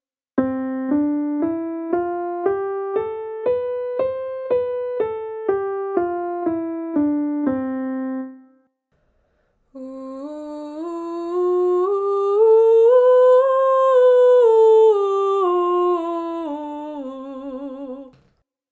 Major Scales
Ex: C4 – D – E – F – G – A – B – C5 – B – A – G – F – E – D – C4